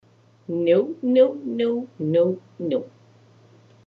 jensk-no-5-raz
• Категория: Отрицание - нет
• Качество: Высокое